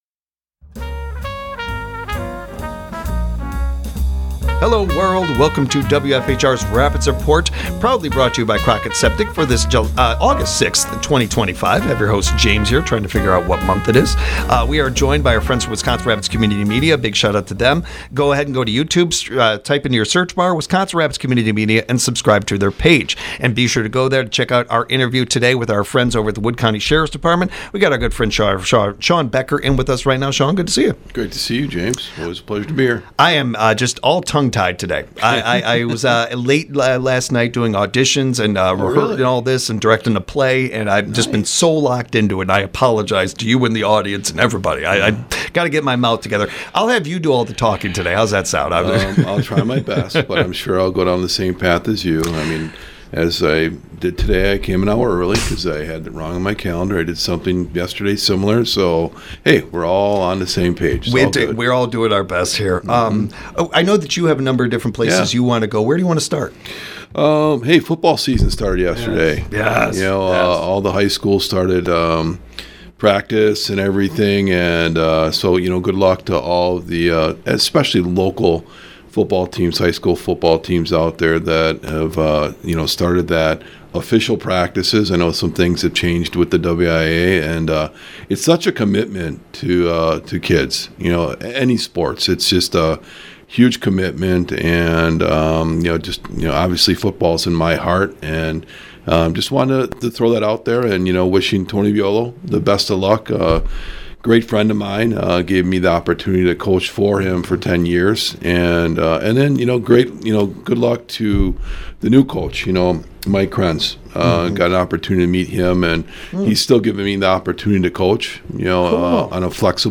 This interview was made in collaboration with Wisconsin Rapids Community Media